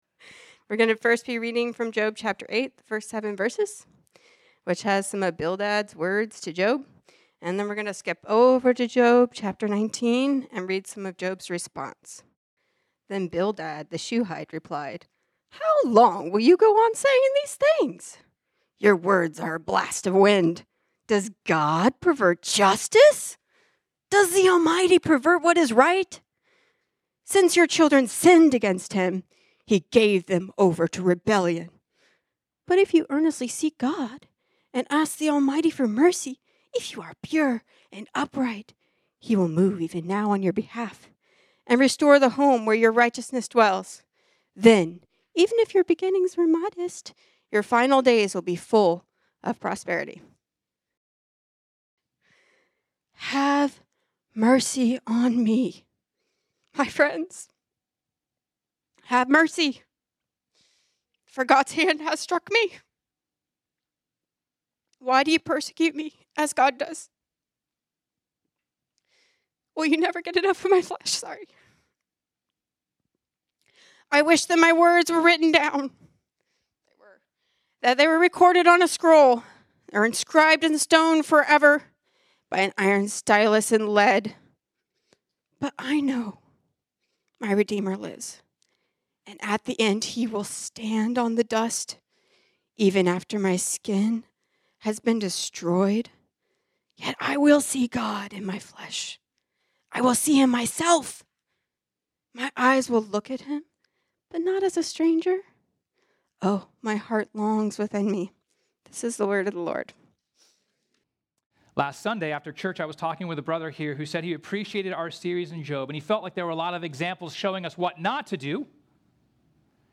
This sermon was originally preached on Sunday, February 1, 2026.